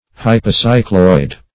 Hypocycloid \Hy`po*cy"cloid\, n. [Pref. hypo- + cycloid: cf. F.